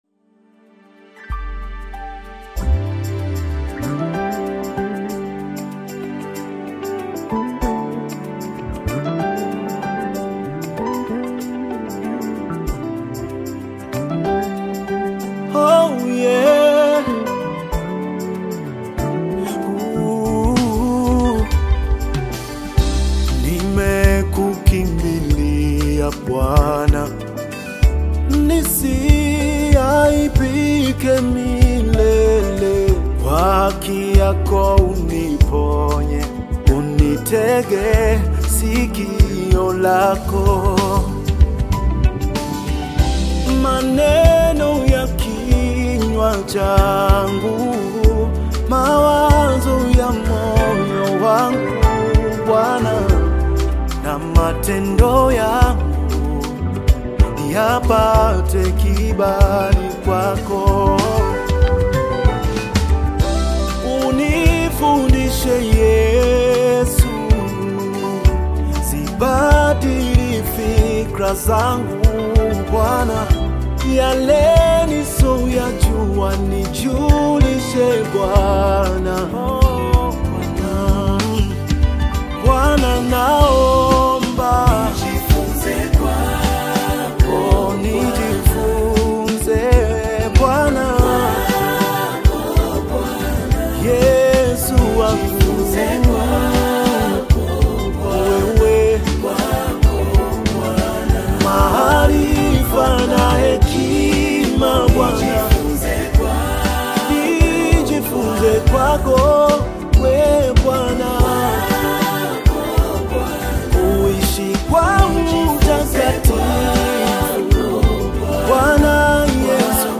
Gospel music track
Tanzanian gospel artists